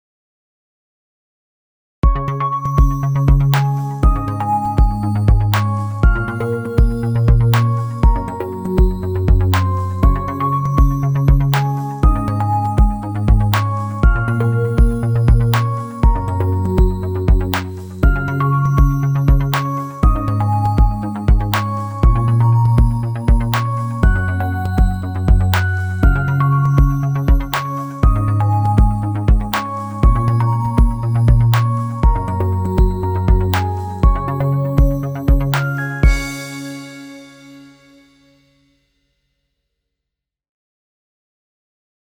PowerPoint soft background music